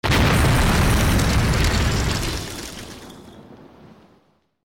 otherdestroyed2.wav